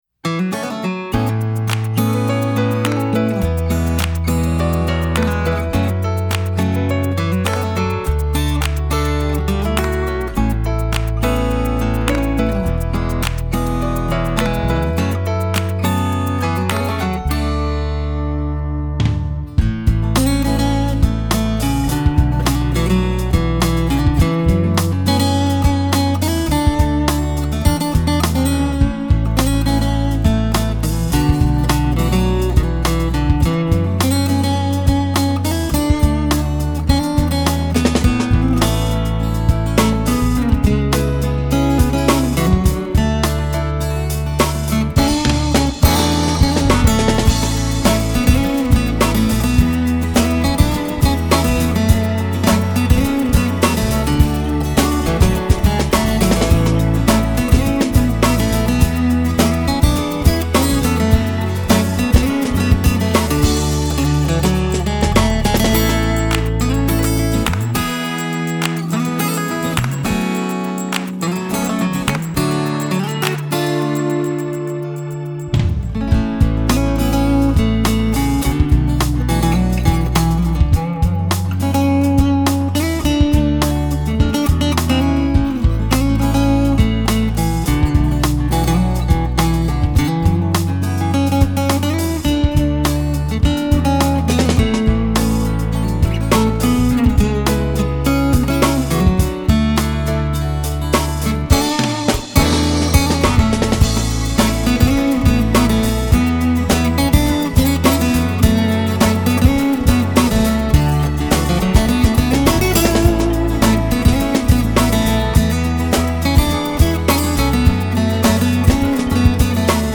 0295-吉他名曲大海爱蓝天.mp3